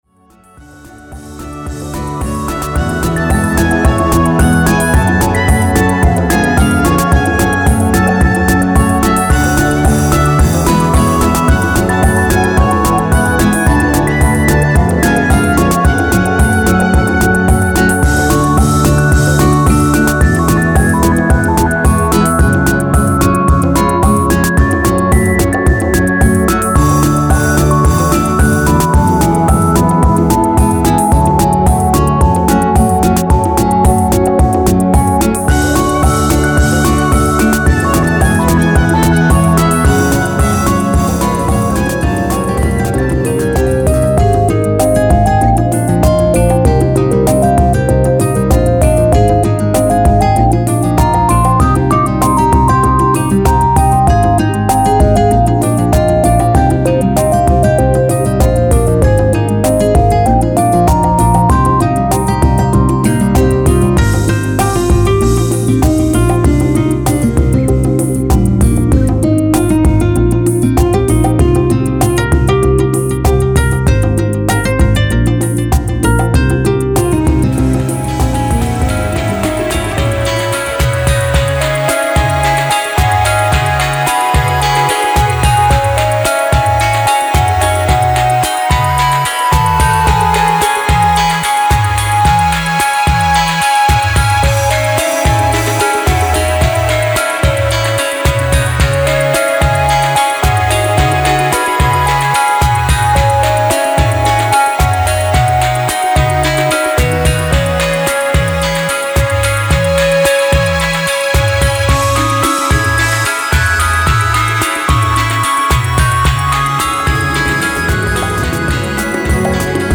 弦とシンセの音色はさらにやさしく、さらにせつなく
サイン波や三角波のなつかしい電子音と、
アコースティックギターやヴァイオリン、コンガなどの生音、
前作よりもやさしく、せつなく、ほんのりあかるく
平均ＢＰＭ102　さらに遅くなりました
全曲クロスフェードデモmp3[4:57]のダウンロード